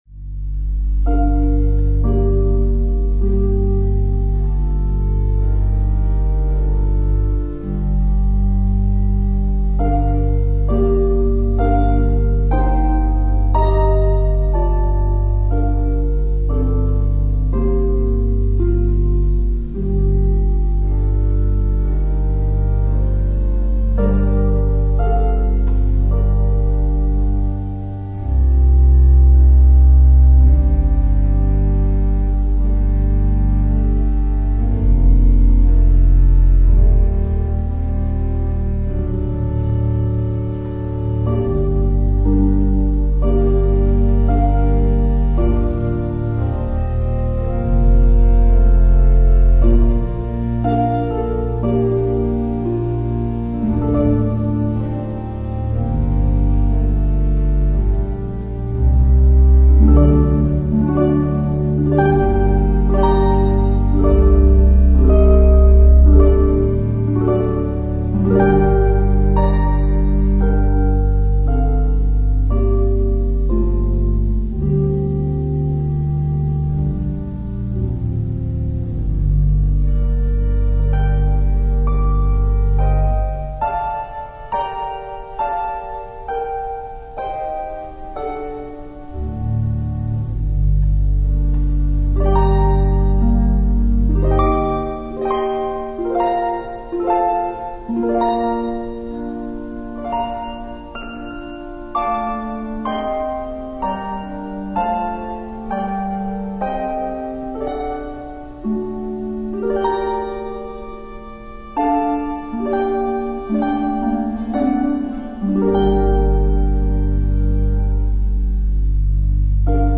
Voicing: Harp and Organ